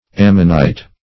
Ammonite \Am"mon*ite\, n. [L. cornu Ammonis born of Ammon; L.